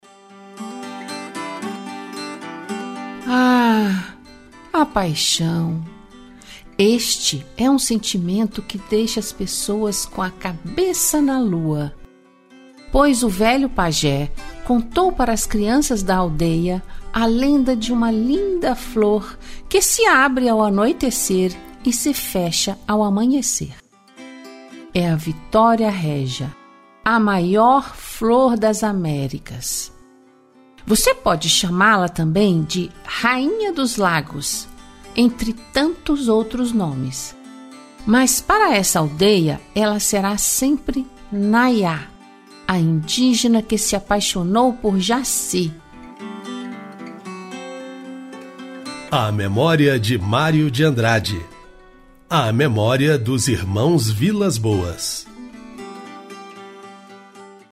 Audiolivro